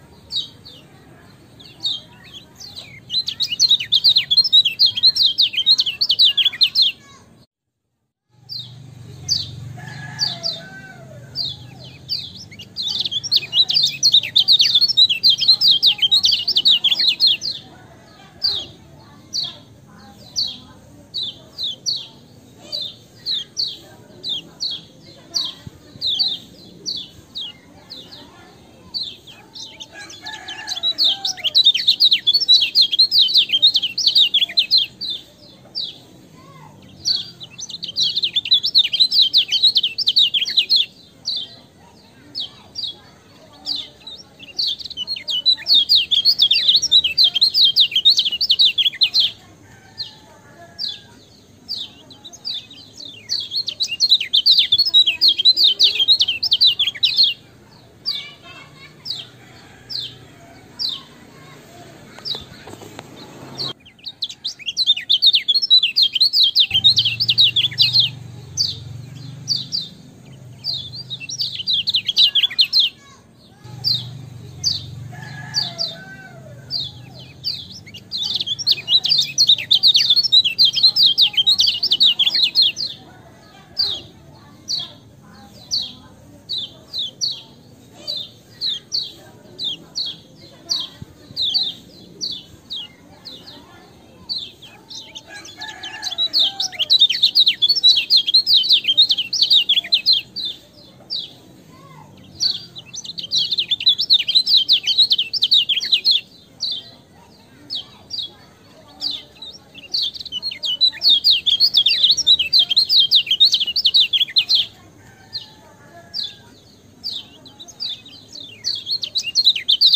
Suara Burung Kecial Kuning
Suara Burung Kecial Kuning Betina Suara Ciblek Kristal Ngebren Panjang
Kategori: Suara burung
Keterangan: Suara burung kecial kuning jernih tersedia untuk di-download dalam format MP3.
suara-burung-kecial-kuning-id-www_tiengdong_com.mp3